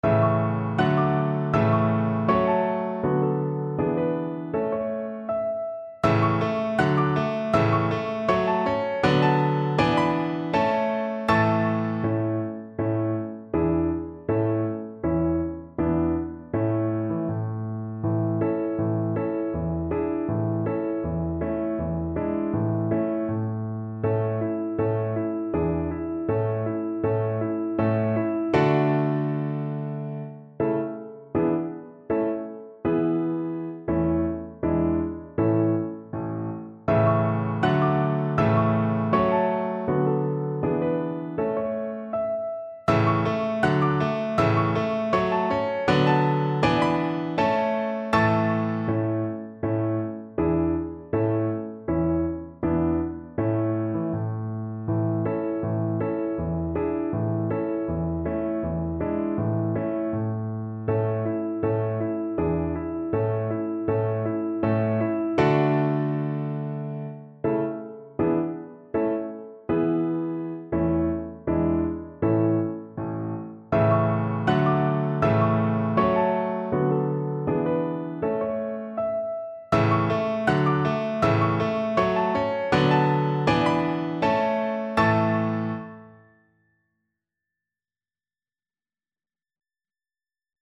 2/4 (View more 2/4 Music)
Andante